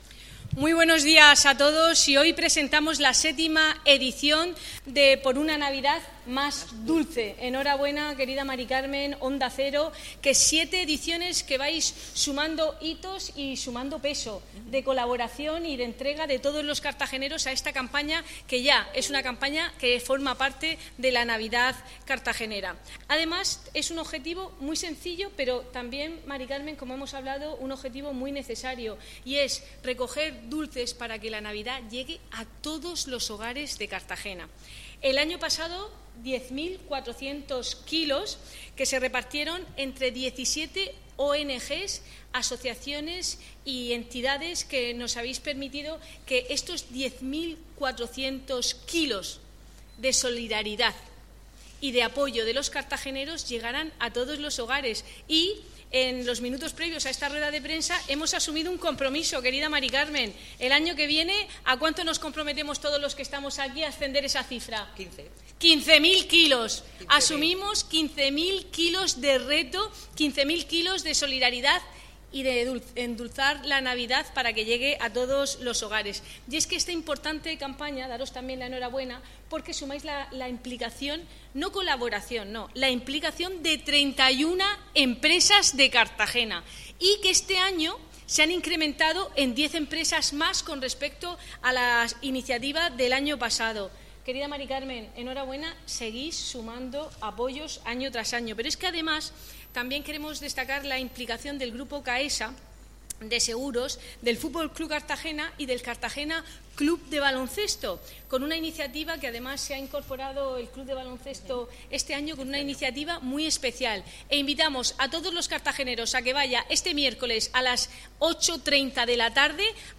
Audio: Presentaci�n de la campa�a Por una Navidad m�s dulce (MP3 - 7,66 MB)